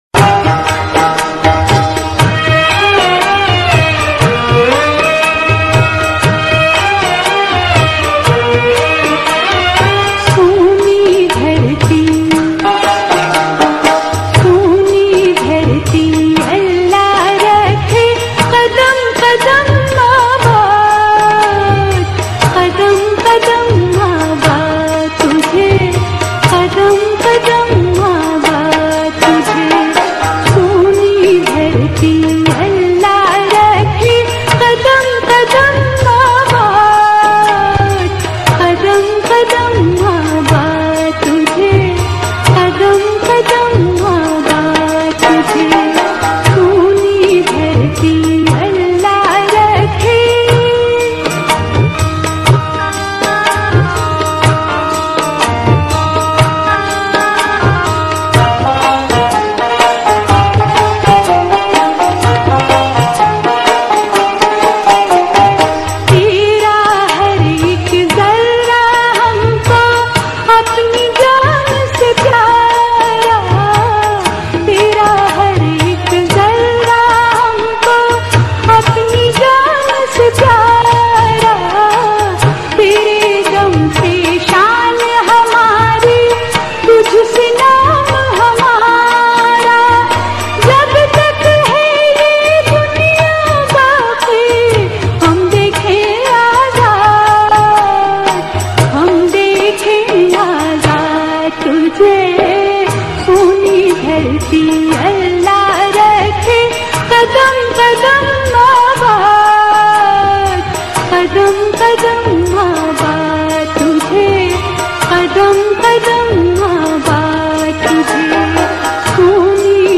national song